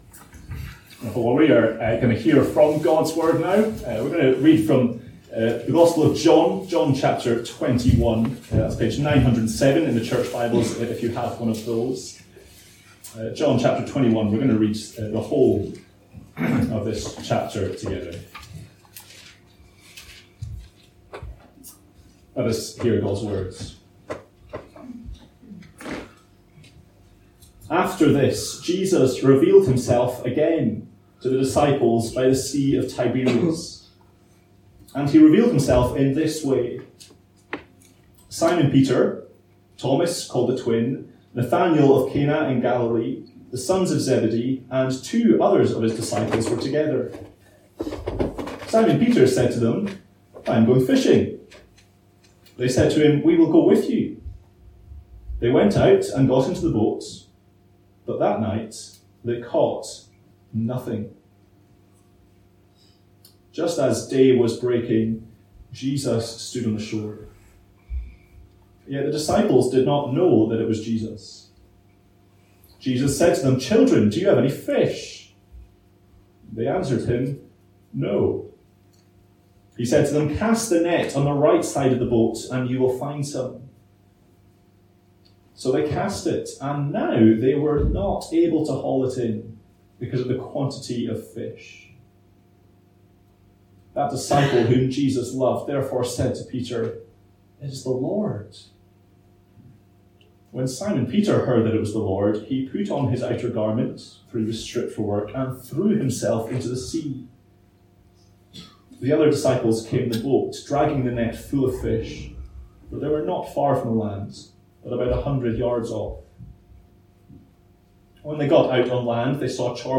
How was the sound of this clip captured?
A link to the video recording of the 11:00am service, and an audio recording of the sermon.